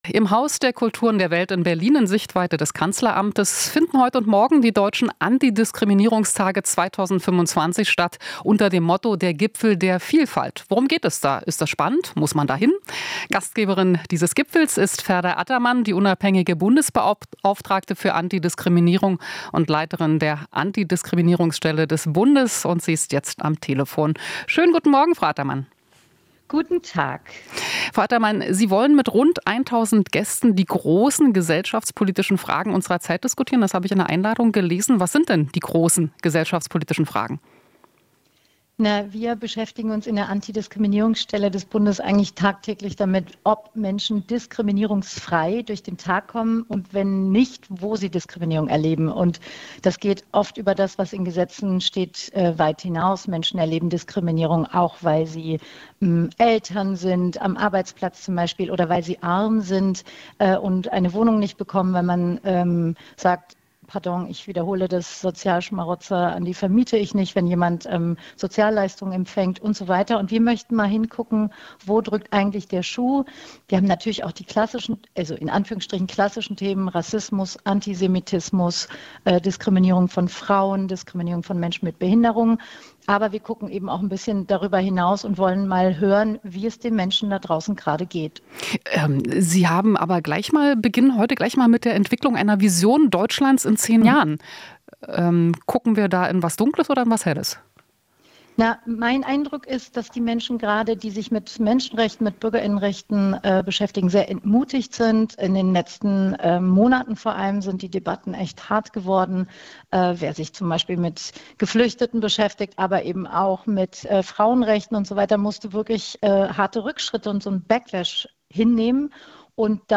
Ferda Ataman, Antidiskriminierungsbeauftragte der Bundesregierung